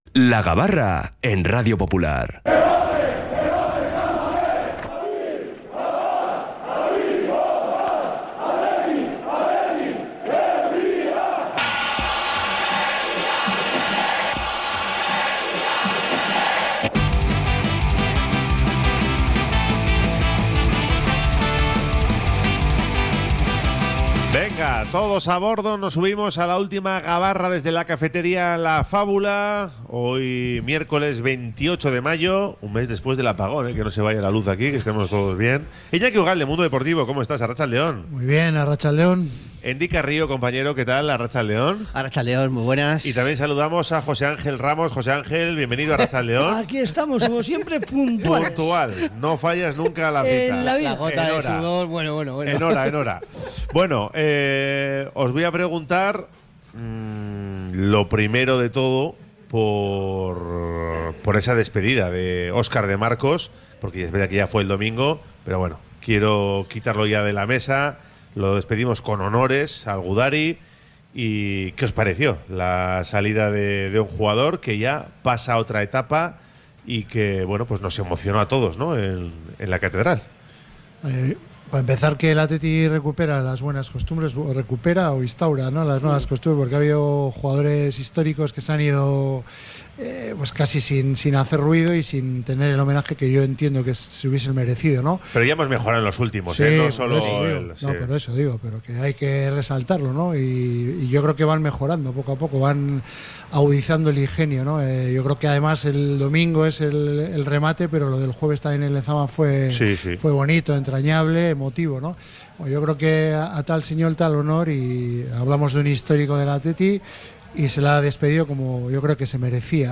La Gabarra 28-05-25 | Última tertulia desde La Fábula